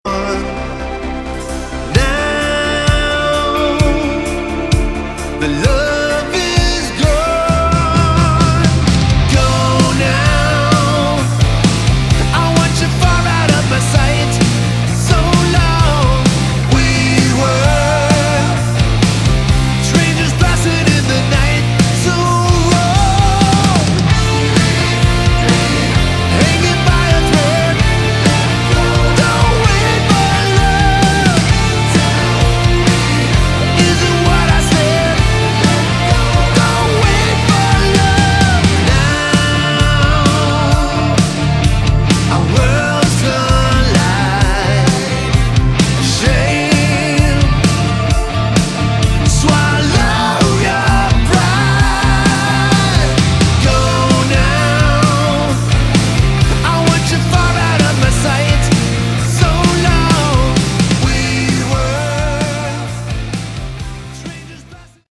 Category: Melodic Rock
lead vocals, bass, keyboards
drums
backing vocals